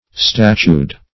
Statued - definition of Statued - synonyms, pronunciation, spelling from Free Dictionary
Statued \Stat"ued\ (st[a^]t"[-u]d), a.